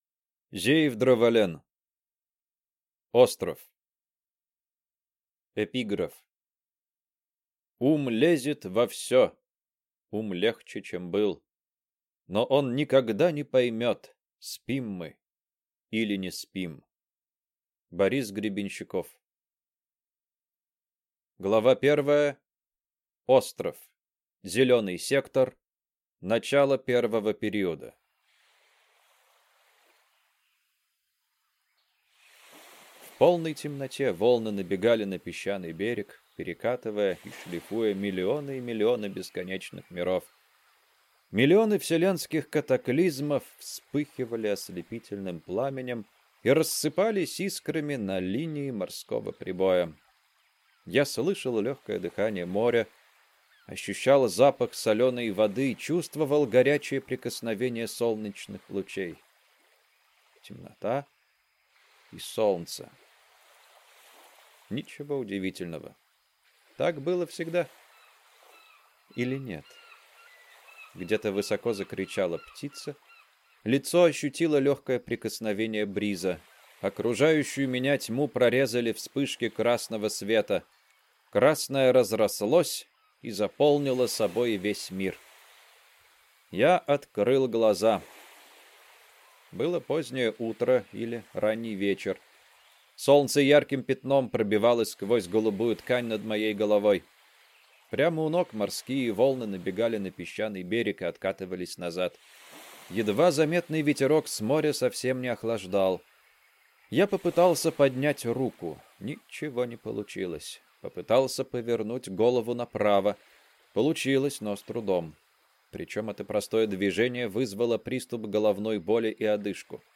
Аудиокнига Остров | Библиотека аудиокниг